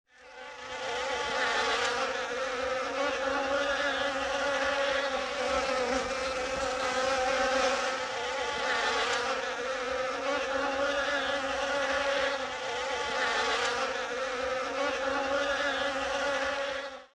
The sound of a swarm of bees - Eğitim Materyalleri - Slaytyerim Slaytlar
the-sound-of-a-swarm-of-bees